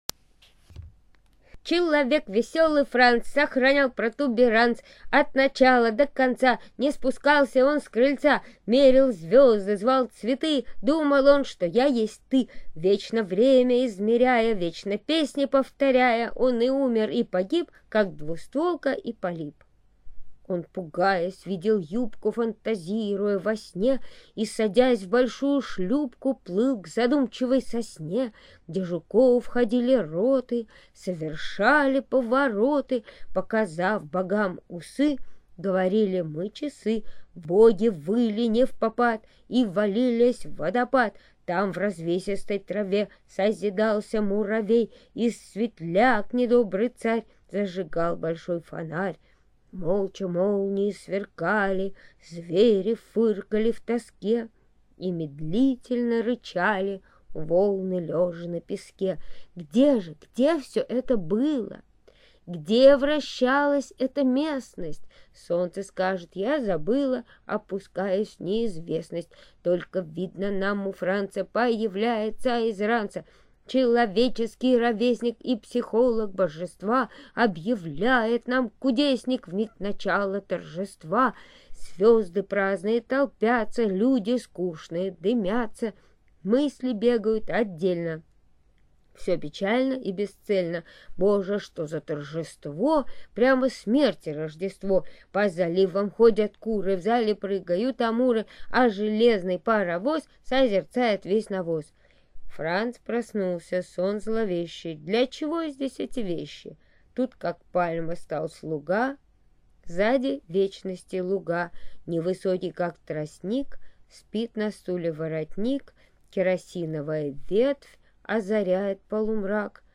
1. «Умка читает Введенского 2013 – 04. Человек весёлый Франц» /